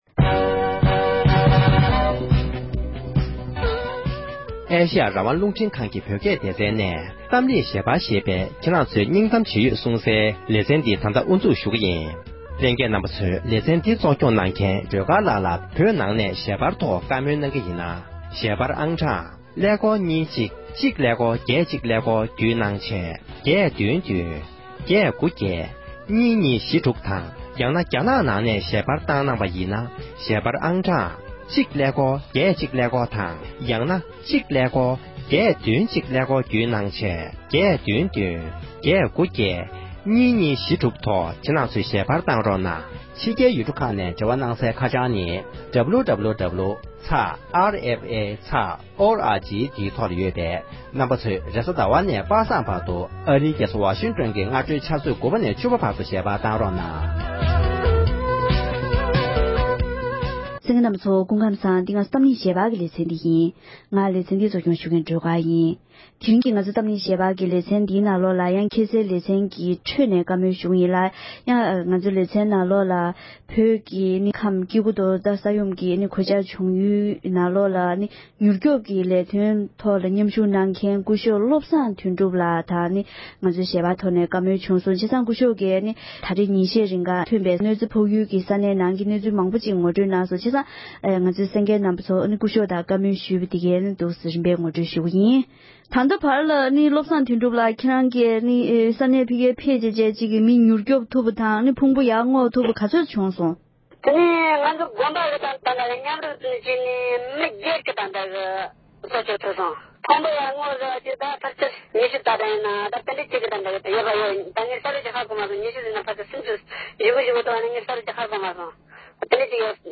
ཡུལ་ཤུལ་གྱི་ས་འགུལ་གནོད་འཚེ་ཕོག་ཡུལ་དུ་དགེ་འདུན་པས་རོགས་སྐྱོབ་གནང་བར་ཕེབས་མཁན་ལ་བཀའ་མོལ་ཞུས་པ།